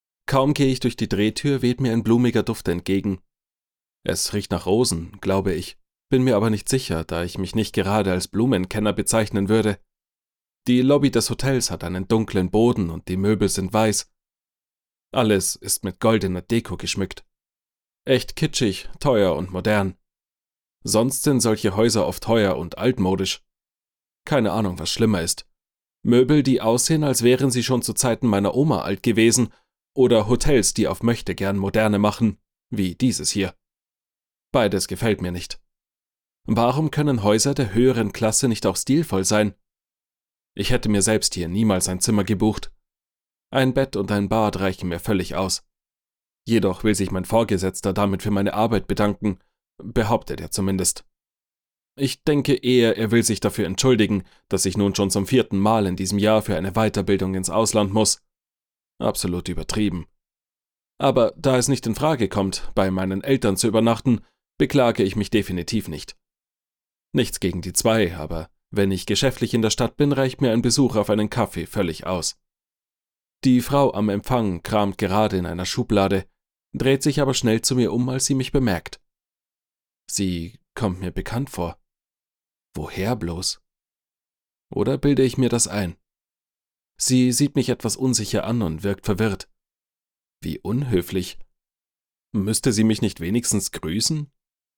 Hörbuch | Romance